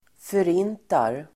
Uttal: [för'in:tar]